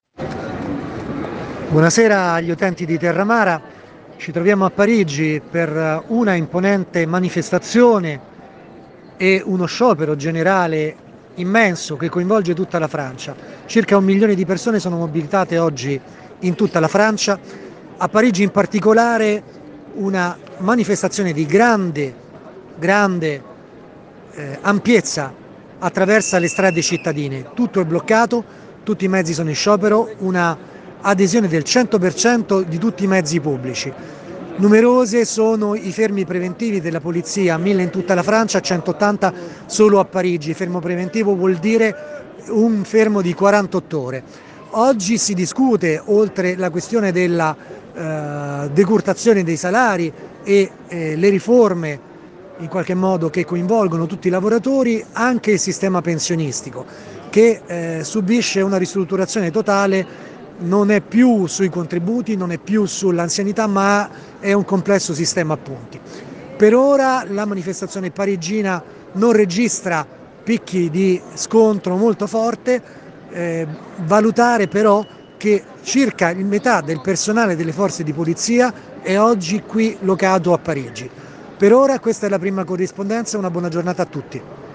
Francesi in movimento (aggiornamenti audio e video). In corso una grossa manifestazione per le strade di Parigi